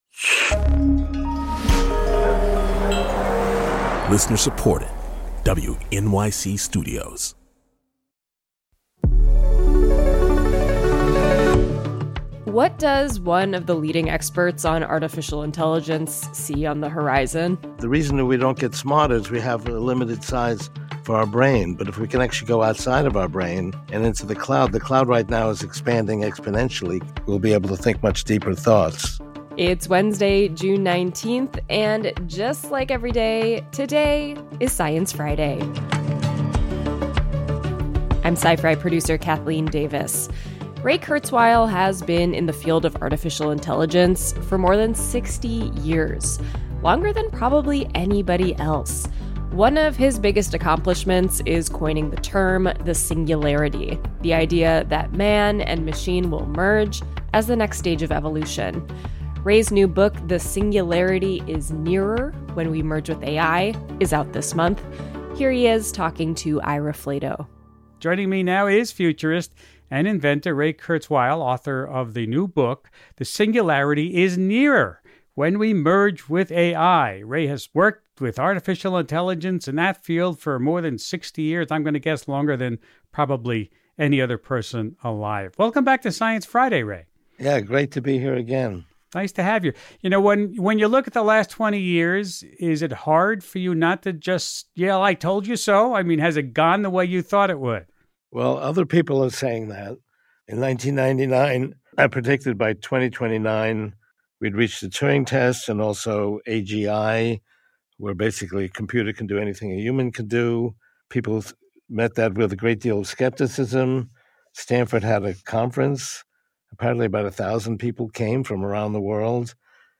Ira Flatow speaks to Kurzweil about the book and his more than six decades of experience in the field of artificial intelligence.